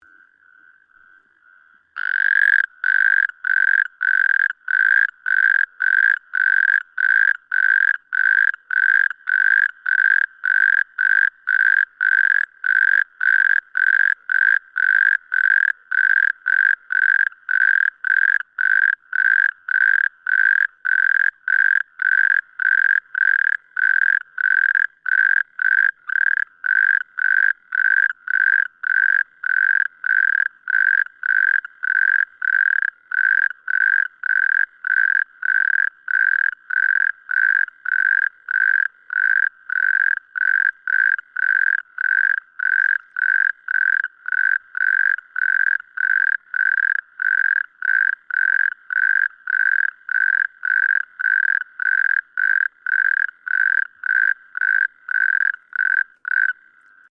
Der Gesang der Maulwurfsgrille wird oft mit dem Ruf der Kreuzkröte verwechselt. Der Unterschied: Die Grille singt ohne Pausen!
Kreuzkroete_Paarungsruf2.mp3